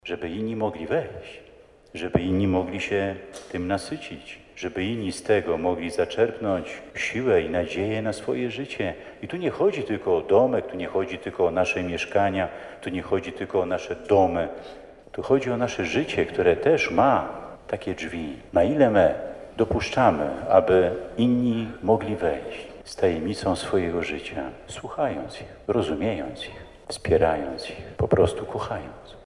Zawsze budujmy nasze życie na skale którą jest Bóg – zachęcał biskup Marek Solarczyk w czasie nawiedzenia kopii Jasnogórskiego Wizerunku w sanktuarium Matki Bożej Loretańskiej.
Bądźmy dla innych drzwiami poprze które spotkają Boga – zachęcał wiernych biskup Marek Solarczyk.